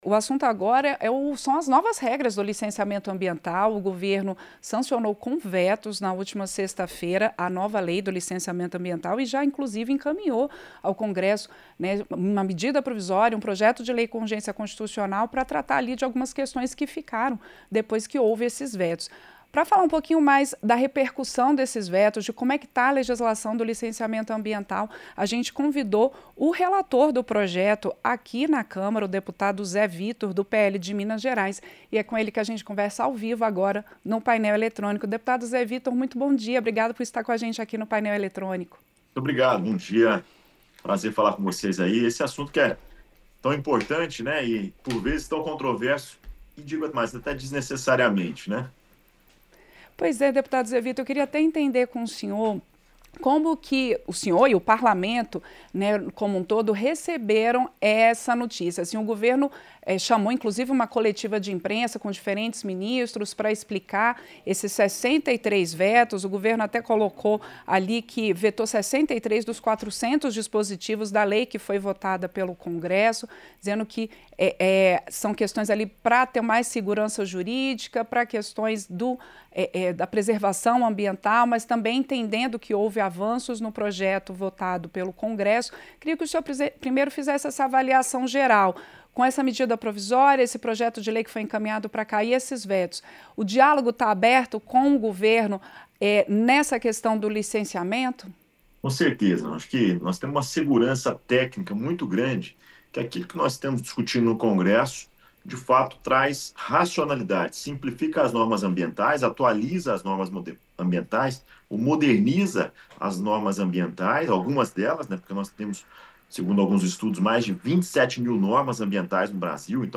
O deputado Zé Vitor (PL-MG) falou ao programa Painel Eletrônico, nesta segunda-feira (11).
Entrevista - Dep. Zé Vitor (PL-MG)